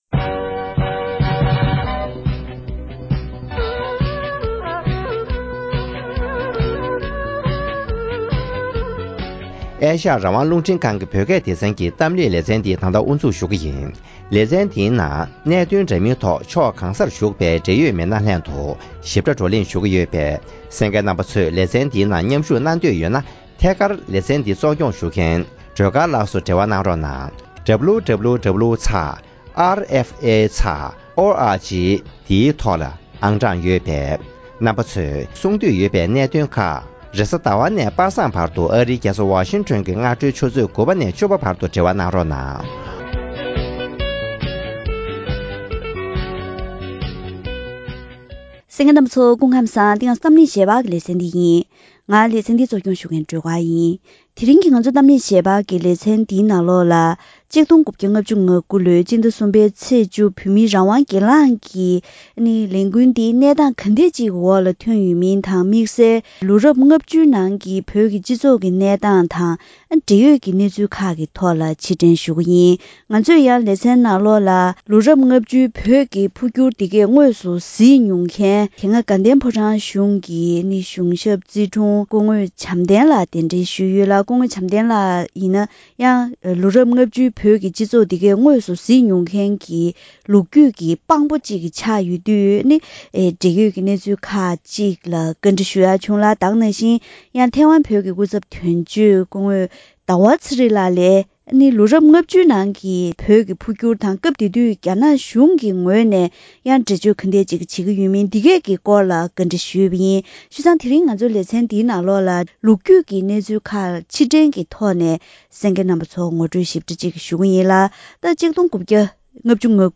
གཏམ་གླེང་ཞལ་པར་ལེ་ཚན་ལོ་ངོ་༦༡སྔོན་ཐོན་པའི་བོད་མིའི་རང་དབང་སྒེར་ལངས་ཀྱི་ལས་འགུལ་འདི་དངོས་སུ་བརྒྱུད་མྱོང་མཁན་དང་། ལོ་རབས་ལྔ་བཅུའི་ནང་བོད་ཀྱི་རྒྱལ་ས་ལྷ་སར་གནས་སྟངས་གང་འདྲའི་འོག་བོད་མི་ཚོས་རྒྱ་ནག་དམར་ཤོག་གི་བཙན་འཛུལ་ལ་འགོག་ཐབས་བྱས་མིན་ཐོག་རྒྱུས་མངའ་ཡོད་མཁན་མི་སྣ་དང་ལྷན་དུ་ལོ་རྒྱུས་ཀྱི་གནས་ཚུལ་ལ་ཕྱིར་དྲན་ཞུས་པ་ཞིག་གསན་རོགས་གནང་།